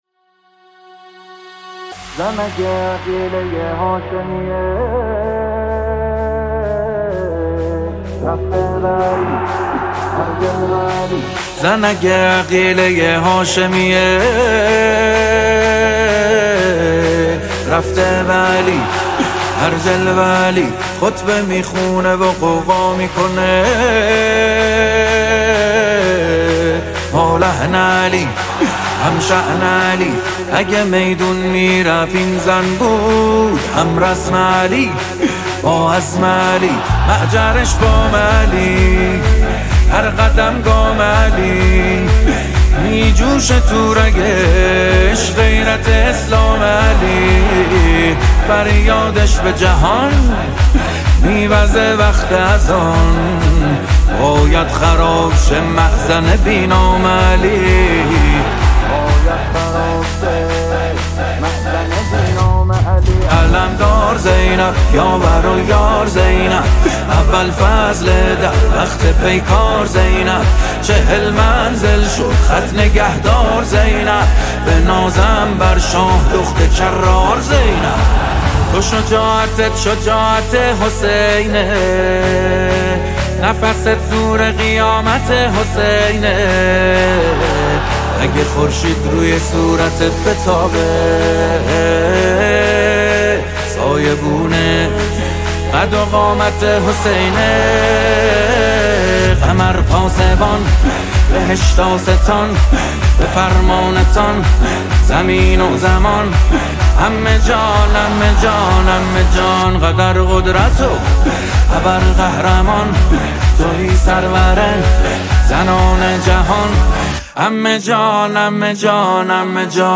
مولودی حضرت زینب